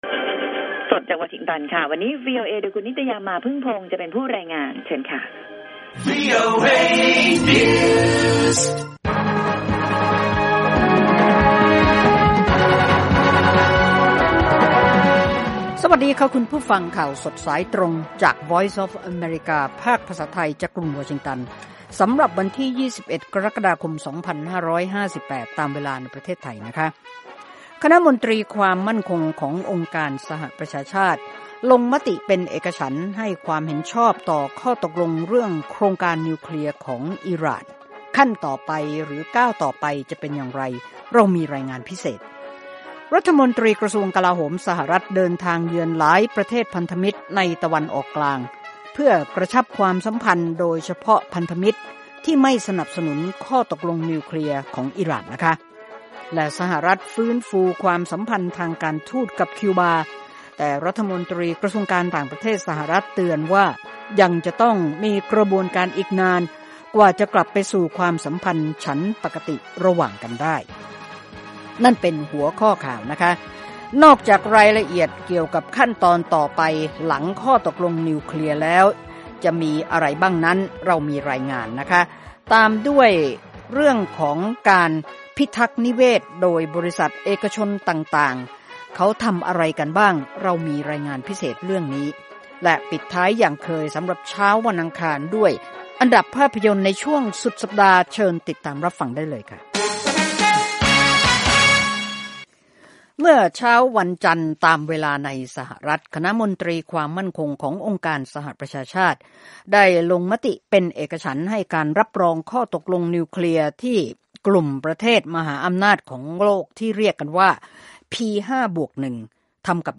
ข่าวสดสายตรงจากวีโอเอ ภาคภาษาไทย 6:30 – 7:00 น. อังคาร ที่ 20 กรกฎาคม 2558